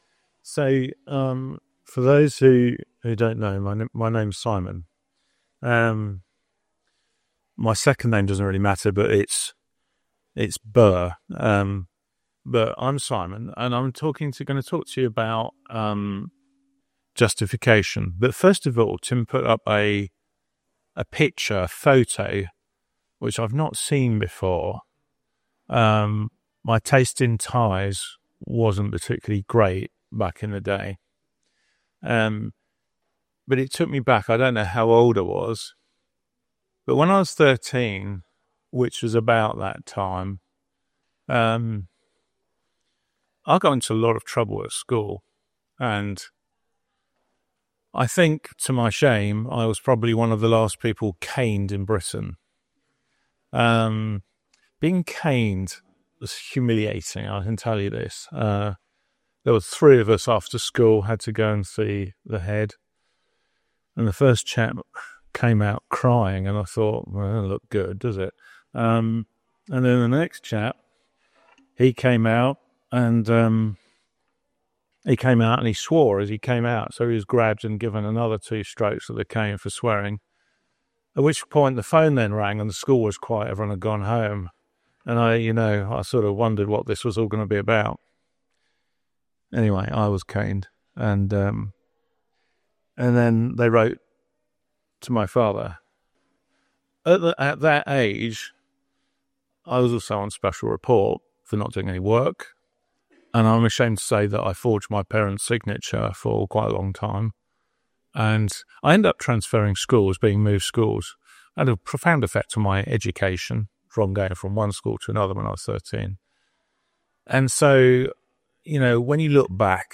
This talk at Refresh 2025 explored how we are made right with God through faith in Jesus. It unpacked the biblical meaning of justification, showing how Christ’s righteousness is credited to us by grace, giving us peace and assurance in our salvation.